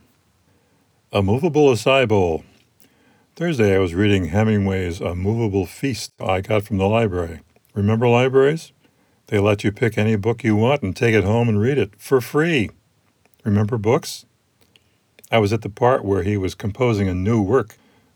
Set up your phone to record the room.
For this kind of recording, my phone records from a microphone on the bottom.
I’m running it in Pressure Zone Configuration.
The bass tones increase and the volume doubles but not the noise.
That’s basically how I shot my Studio B (garage) sound test.